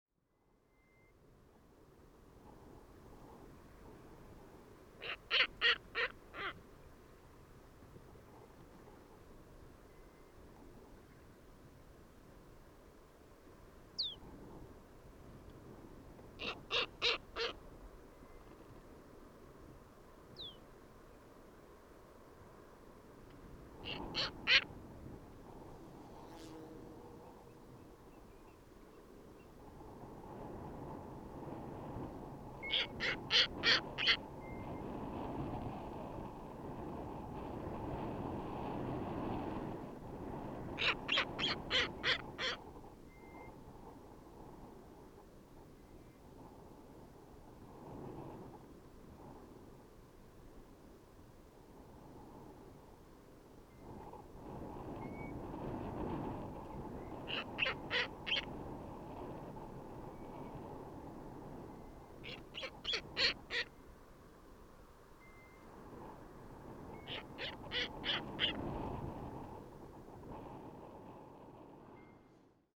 Звуки белой совы
Звук белой совы при виде опасности